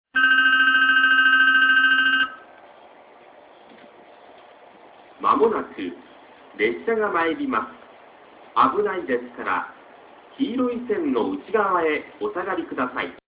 接近放送　男声